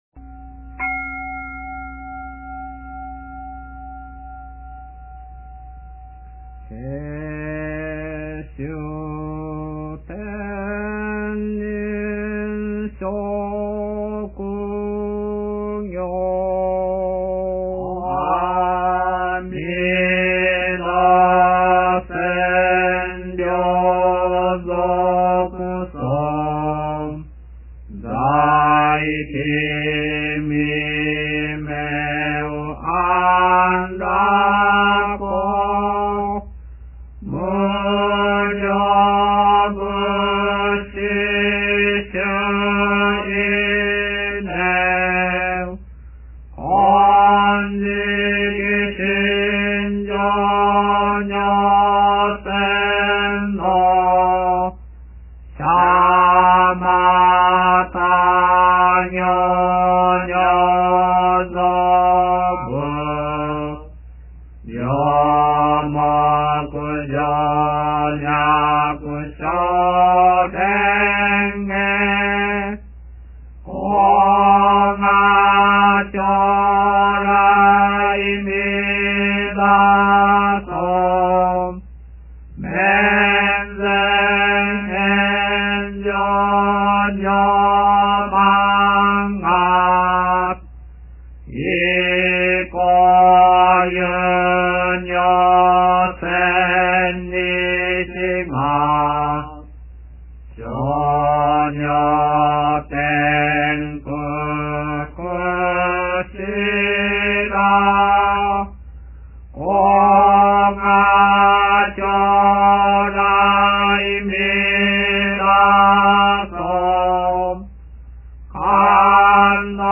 As linhas que começam com '#' indicam aquelas que são lidas apenas pelo líder.
Em '&', o canto diminui para quase metade da velocidade.